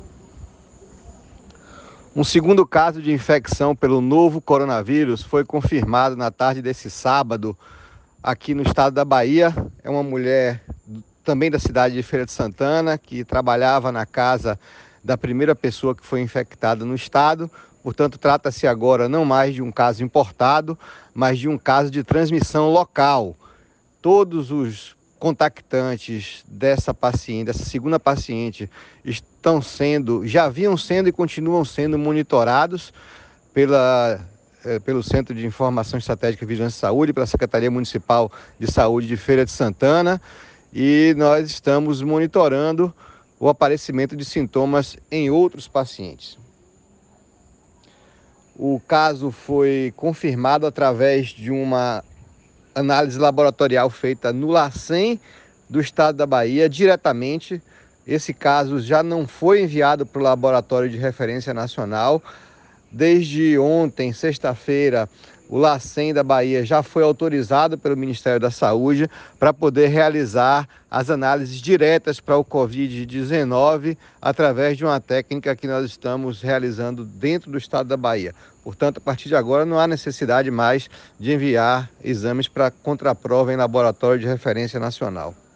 Confira abaixo o áudio do Secretário da Saúde do Estado, Fábio Vilas-Boas, sobre o caso.